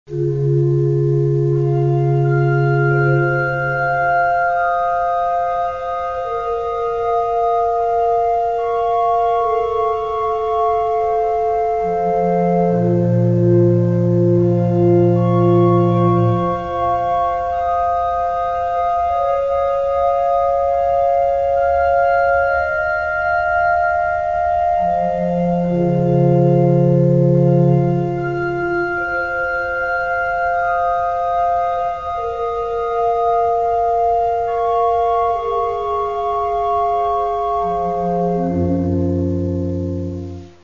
Каталог -> Классическая -> Нео, модерн, авангард